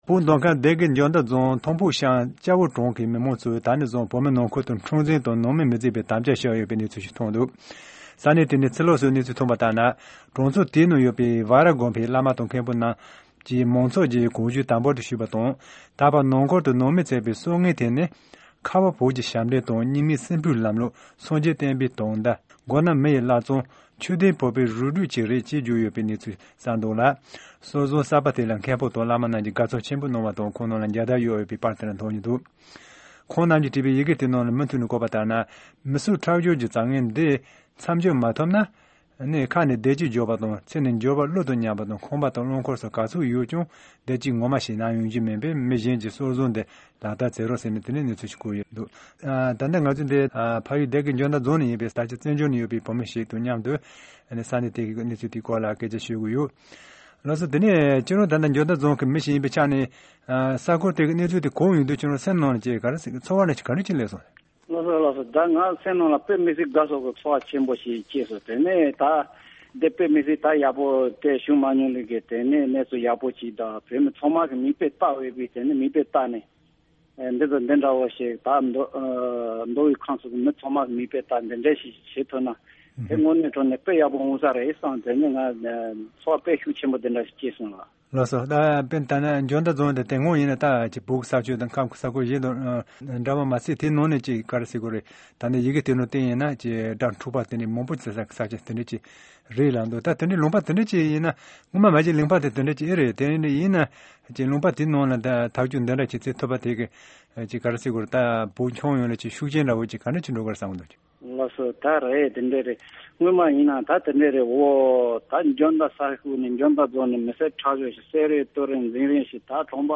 ཕྱོགས་བསྒྲིགས་དང་སྙན་སྒྲོན་ཞུ་གནང་གི་རེད།